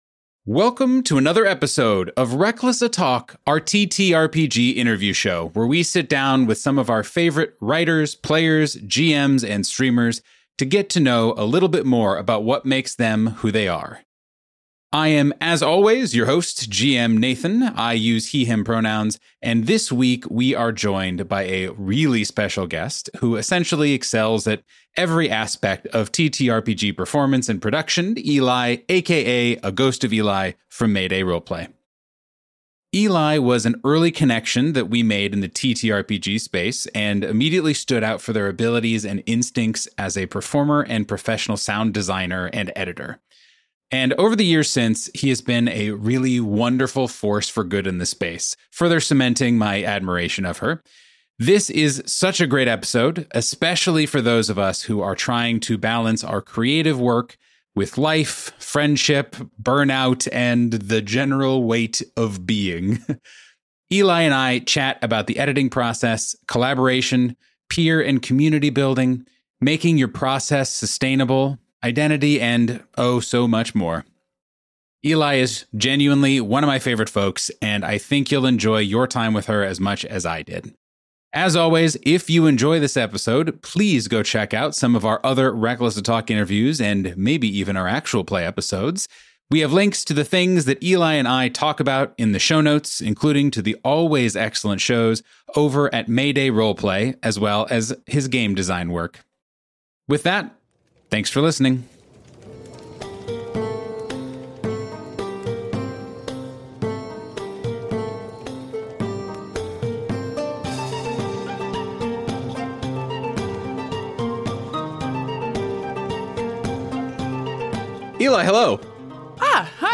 Reckless Attack is a Dungeons and Dragons 5th Edition Real Play podcast, hosted by a group of cheery, diverse, regular folks in Chicago who love their game and want to share it with you.